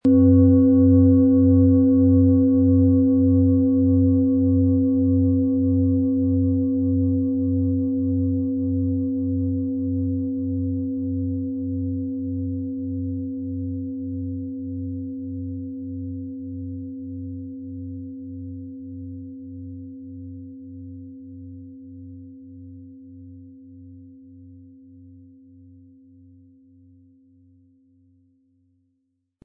Von Hand getriebene tibetanische Planetenschale Biorhythmus Geist.
• Mittlerer Ton: DNA
• Höchster Ton: Delfin
Im Sound-Player - Jetzt reinhören können Sie den Original-Ton genau dieser Schale anhören.
den einzigartigen Klang und das außerordentliche, bewegende Schwingen der traditionsreichen Handarbeit.
PlanetentöneBiorythmus Geist & DNA & Delfin (Höchster Ton)
MaterialBronze